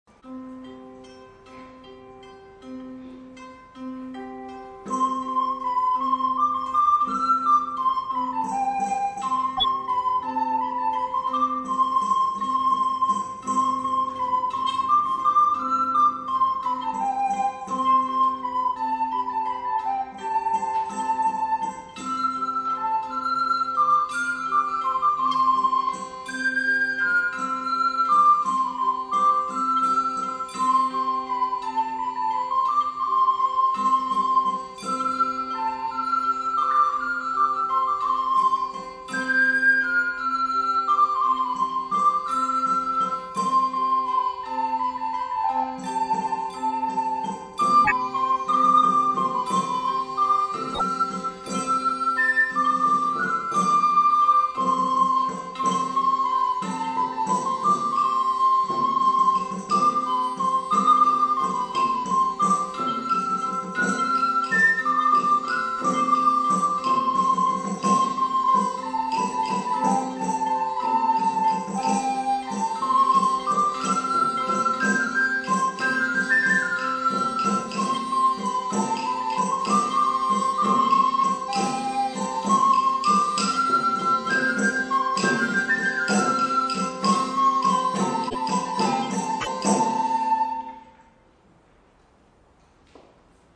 Click to download MP3 recordings of past concerts.